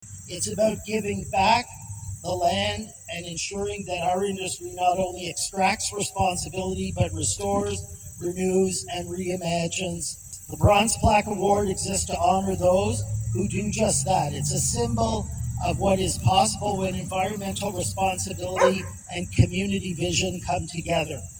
At a ceremony Tuesday morning, the Ontario Stone, Sand & Gravel Association (OSSGA) announced Lake Margaret is the recipient of their 2025 Bronze Plaque Award.